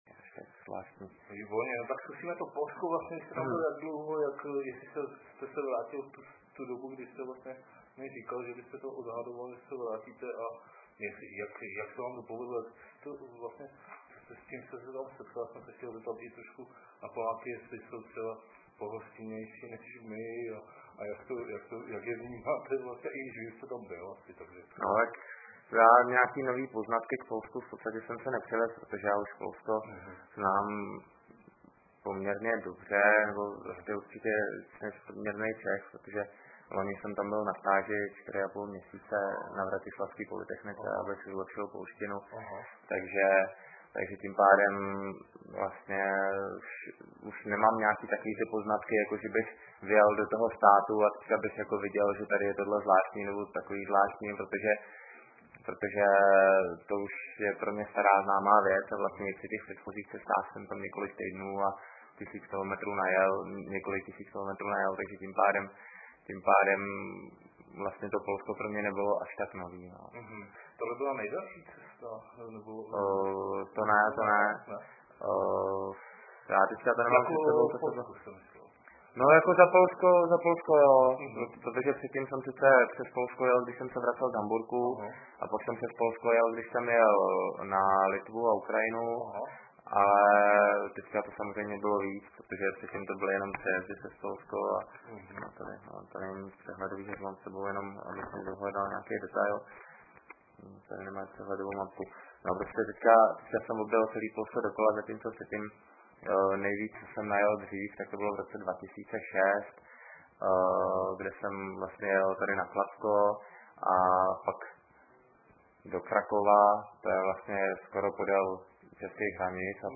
Je to zvukový záznam rozhovoru pro noviny stažený z diktafonu – tzn. mluvím, jak mi zobák narostl; bez osnovy, bez scénáře, bez přípravy; sam si skáču do řeči, mnoho témat zůstalo díky tématickým odbočkám nakousnutých a nezodpovězených.